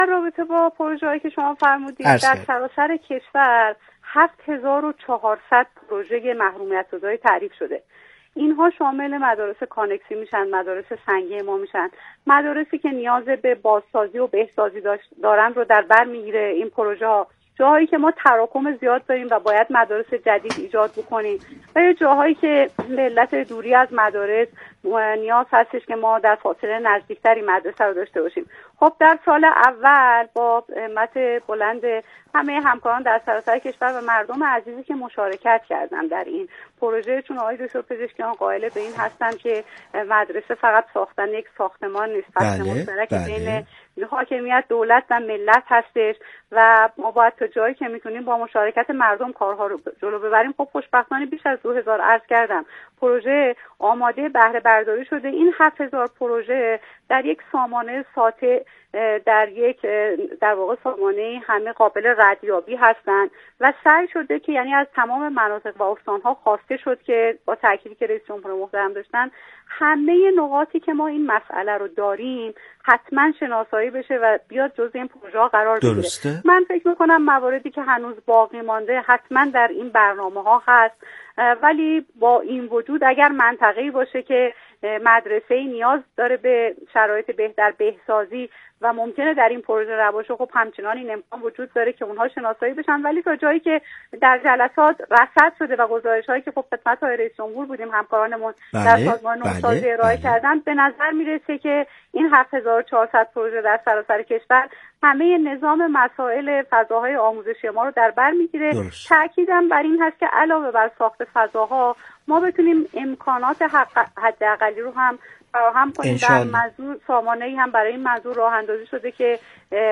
ایکنا به پاس همین اهمیت والا و با درک ژرف از این مسئولیت خطیر، به گفت‌وگو با رضوان حکیم‌زاده، معاون آموزش ابتدایی وزارت آموزش و پرورش نشسته است تا از روایت‌های او در زمینه تحول آموزشی، عدالت محوری و همگامی با نظام آموزش و پروش با فناوری‌های روز در مسیر برداشتن گامی هر چند کوچک در راه اعتلای آموزش و پرورش میهن عزیزمان بگوید و بشنود.